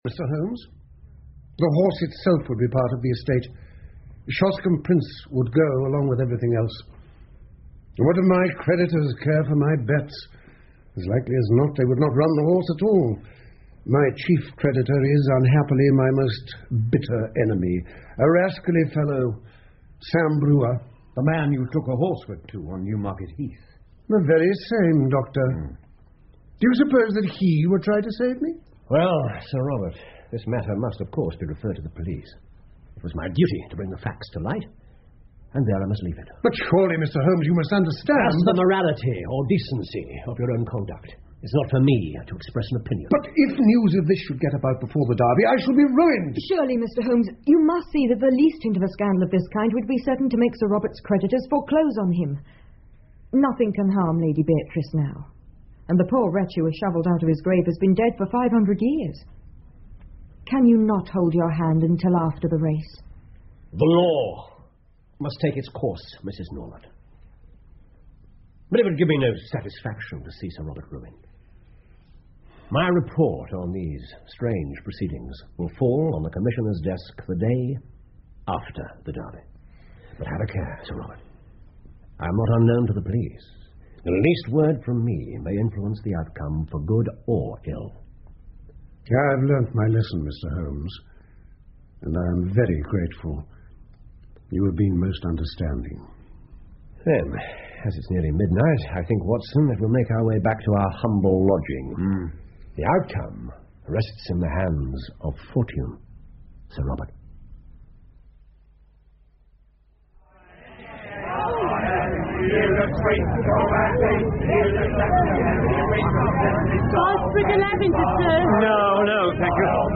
福尔摩斯广播剧 Shoscombe Old Place 8 听力文件下载—在线英语听力室
在线英语听力室福尔摩斯广播剧 Shoscombe Old Place 8的听力文件下载,英语有声读物,英文广播剧-在线英语听力室